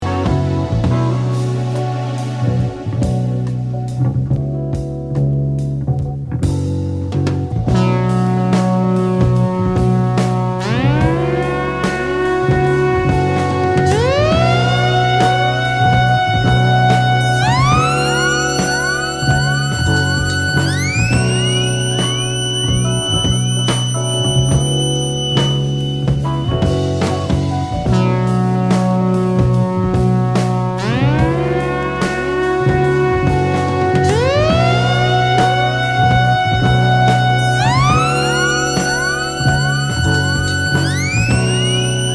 Tag       OTHER REAR GROOVE/FUNK